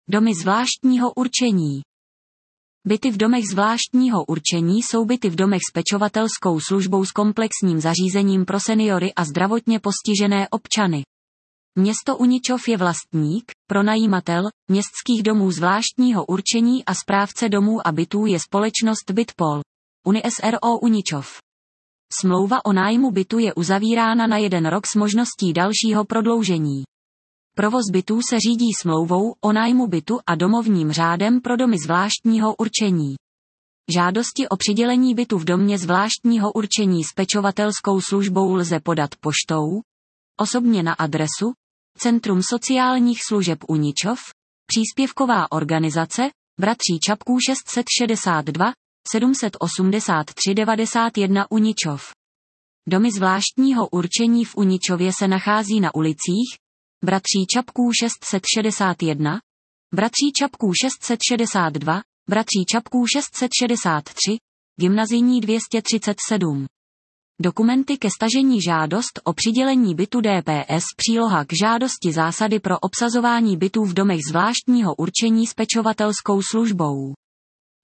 PŘEČÍST NAHLAS: